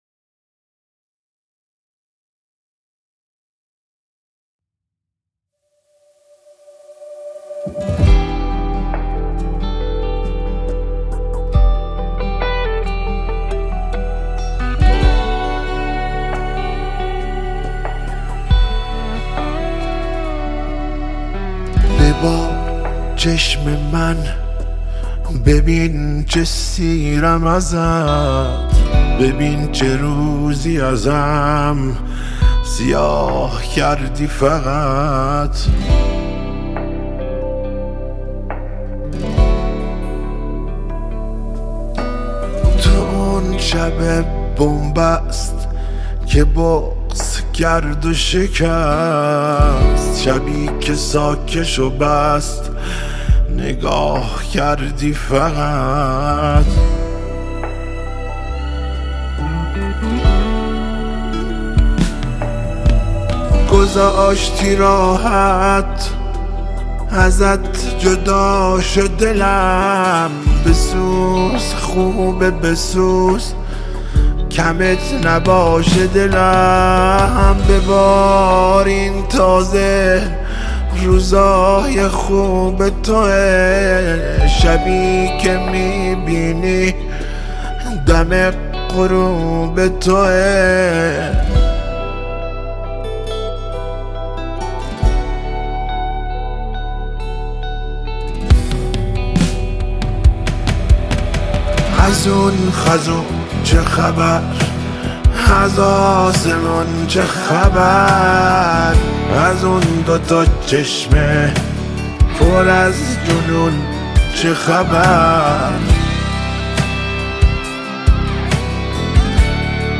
موزیک ویدیو غمگین